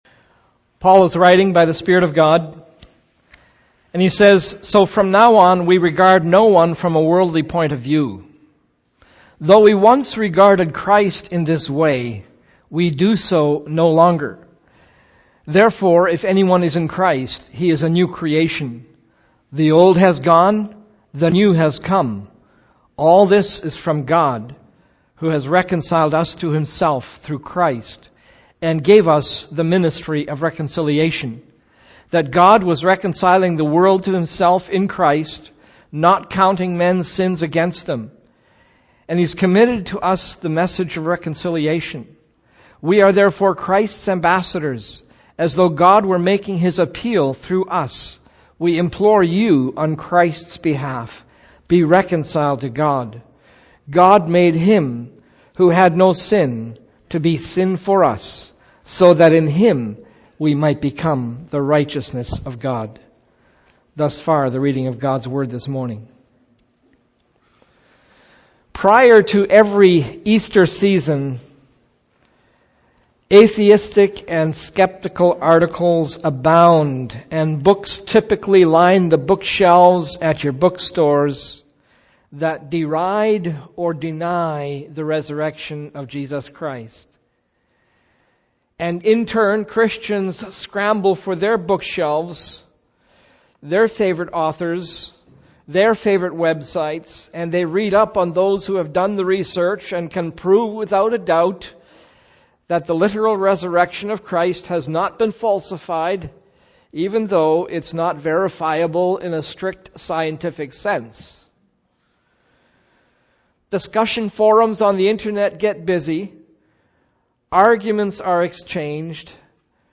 Easter Sunday Sermon from 2 Corinthians 5.